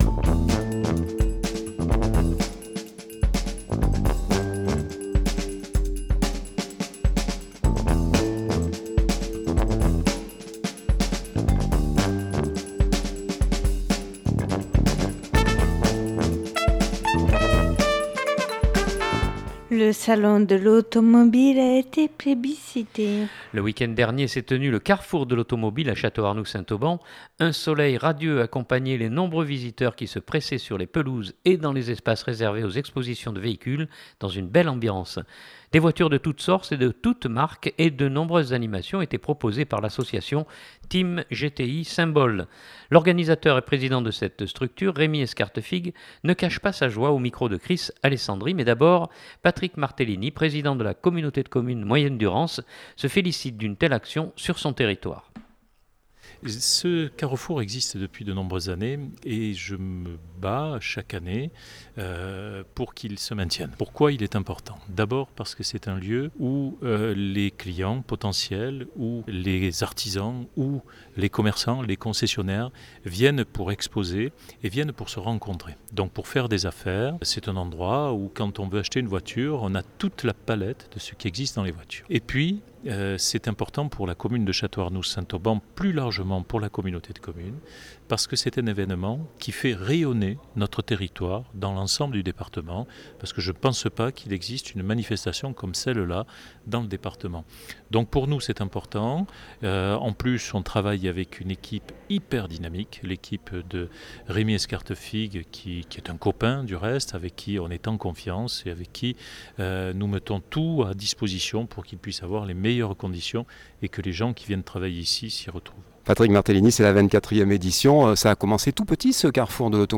Mais d’abord, Patrick Martellini Président de la communauté de Commune de Moyenne-Durance se félicite d’une telle action sur son territoire.